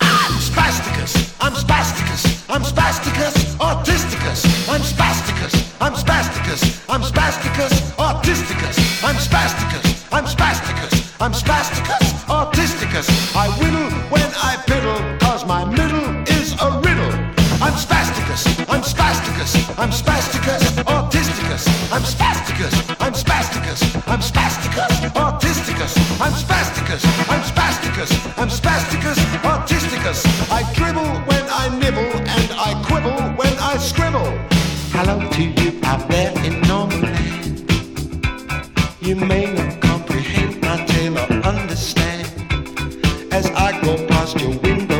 バハマ、コンパス・ポイント・スタジオ録音。
南国感有り、気怠い男たちな印象有り、そしてちょっぴり哀愁。
Rock, Electronic, Reggae　France　12inchレコード　33rpm　Stereo